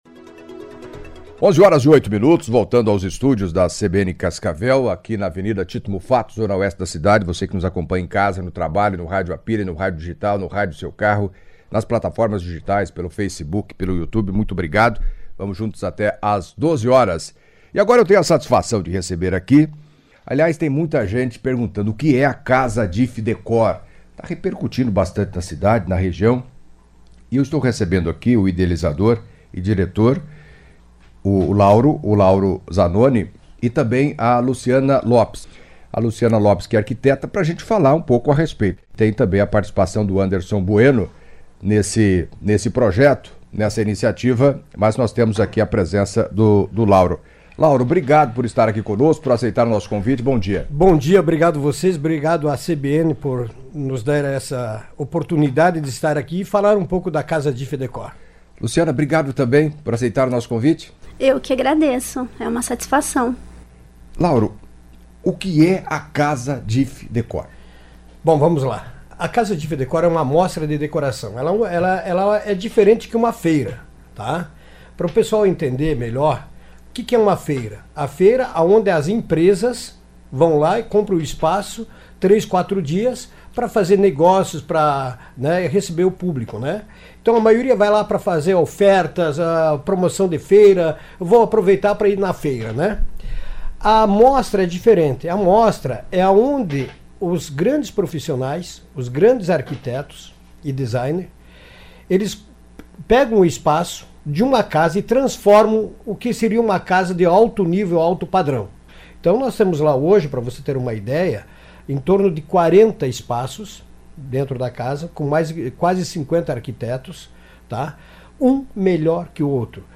Editoriais
Entrevista